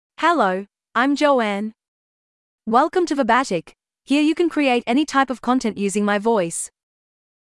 FemaleEnglish (Australia)
Joanne is a female AI voice for English (Australia).
Voice sample
Female
English (Australia)